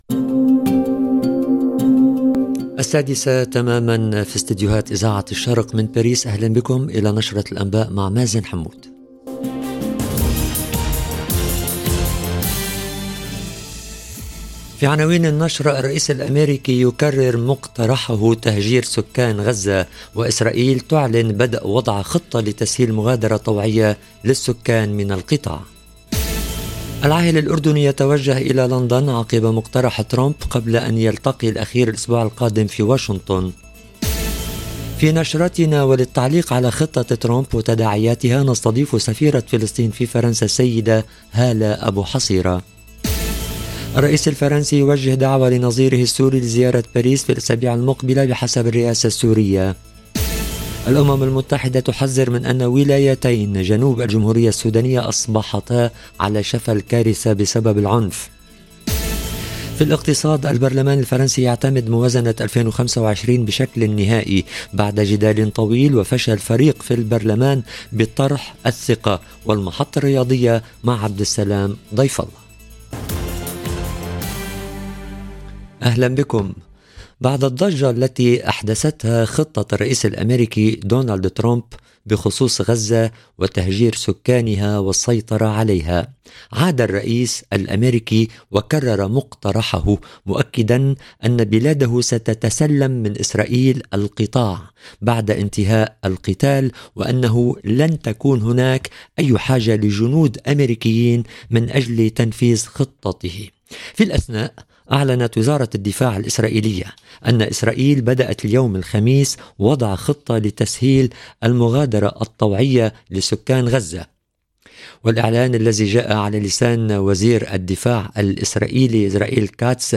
في نشرتنا، وللتعليق على خطة ترامب وتداعياتها، نستضيف سفيرة فلسطين في فرنسا السيدة هالة أبو حصيرة.